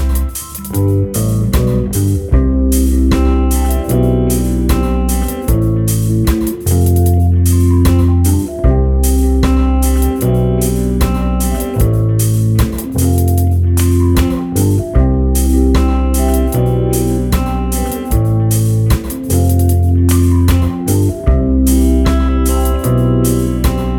Live Version With no Backing Vocals Reggae 4:16 Buy £1.50